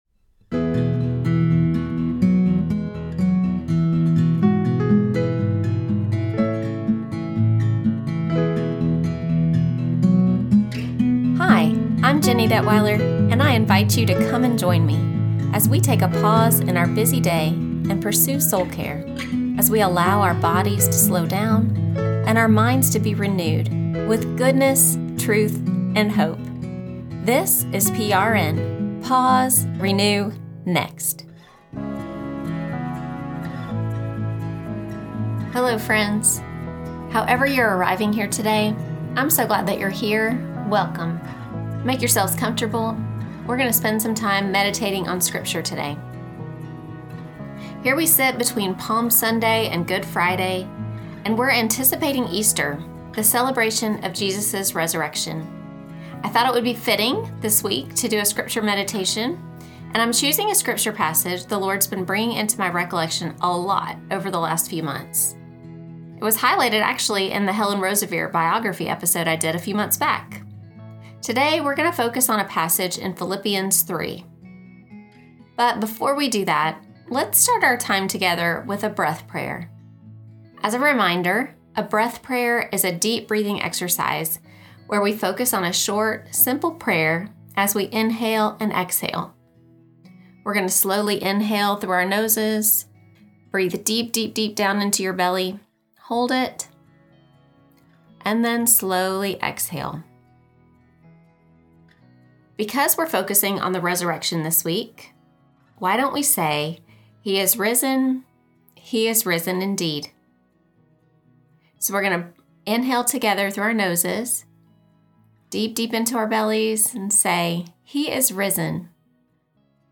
Soul-Care Reflections: A Scripture Meditation on Resurrection & Suffering
We’ll begin our time together with a breath prayer and then transition into the reflection and meditation time. If you’d like to follow along in your Bible, we’ll be reading Philippians 3: 7-16 together, and we’ll spend the most time on verses 10 and 11.